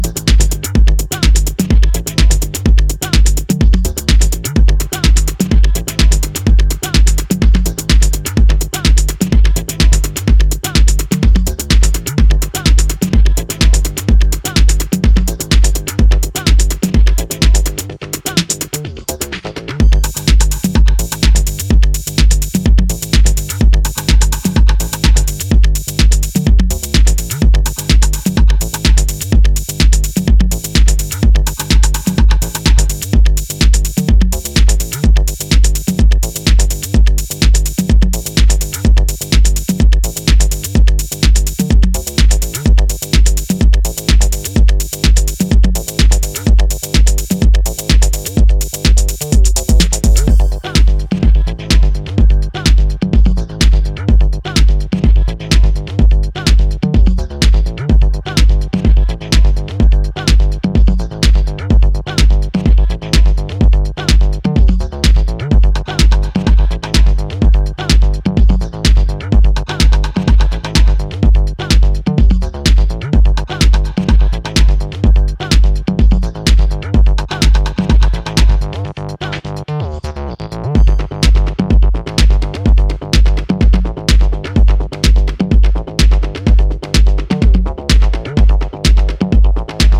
an acid-kissed piece of Techno